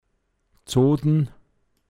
pinzgauer mundart